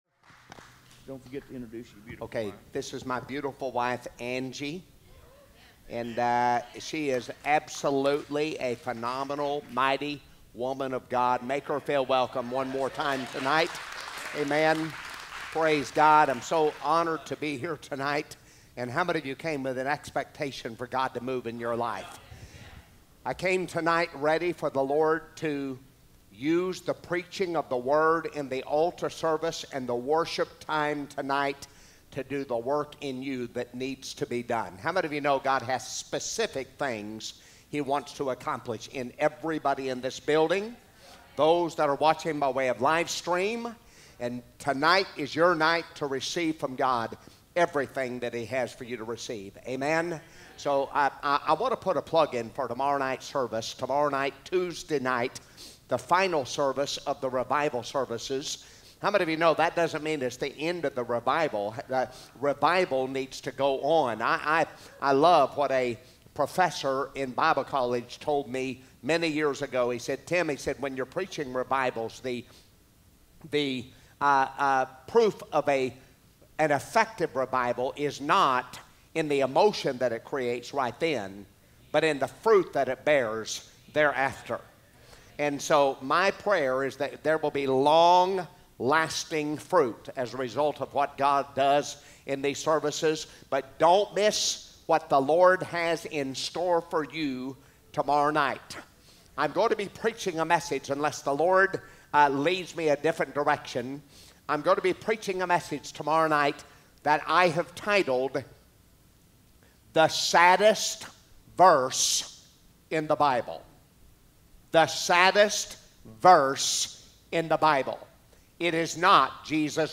From Series: "Fall Revival 2023"